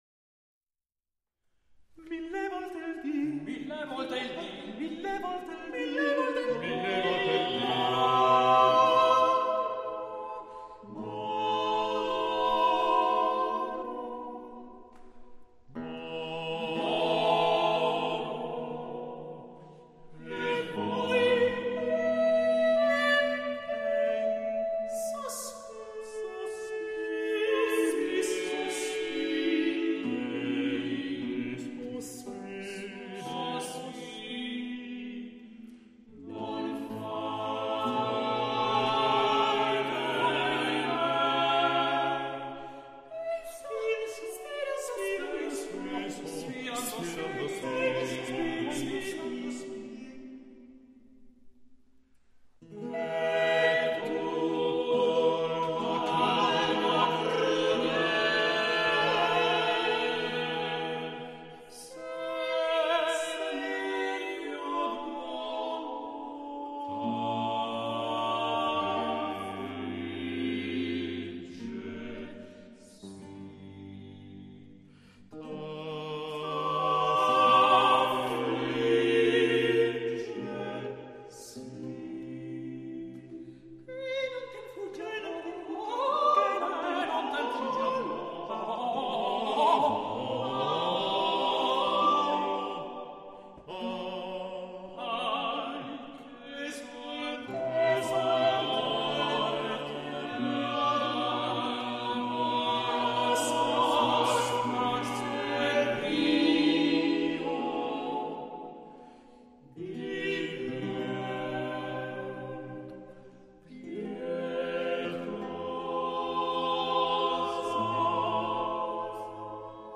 Gesualdo, Mille volte il dì moro | in Libro VI delli madrigali a 5 voci (1613)
— Il complesso barocco, Alan Curtis | Simphonia, 1995